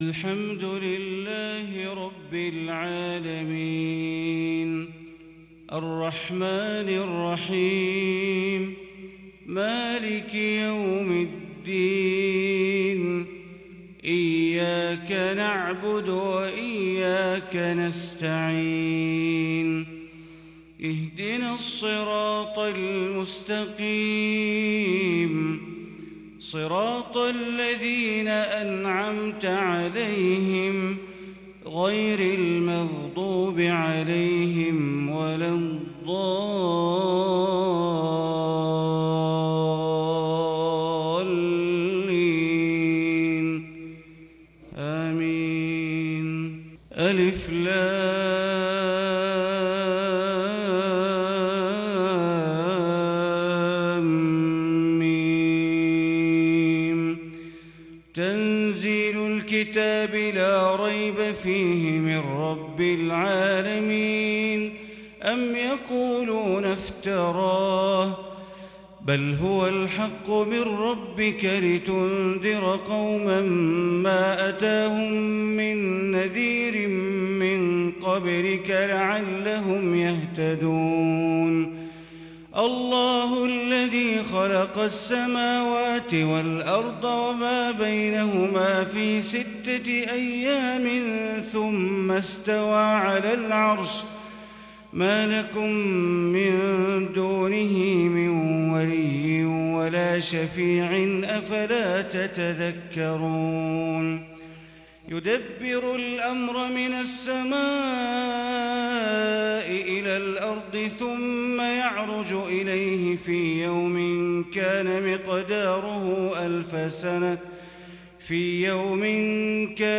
صلاة الفجر للشيخ بندر بليلة 5 ربيع الآخر 1442 هـ
تِلَاوَات الْحَرَمَيْن .